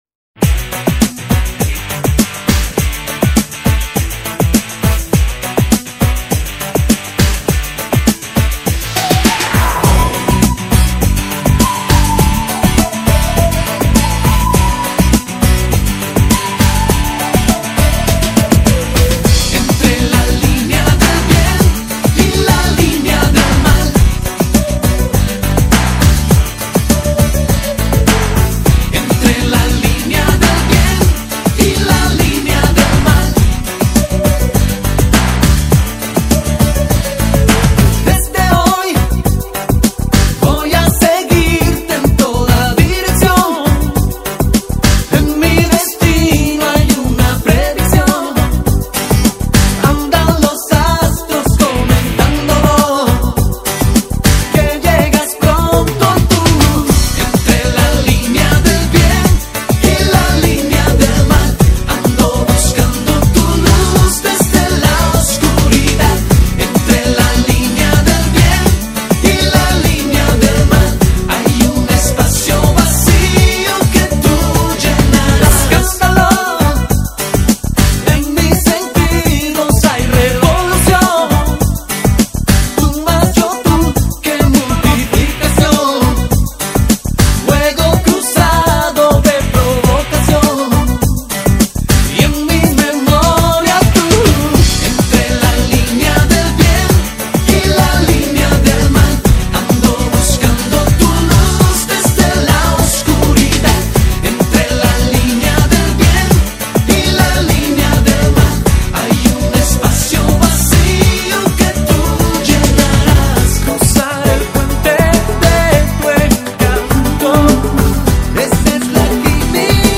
Carpeta: Lentos en español mp3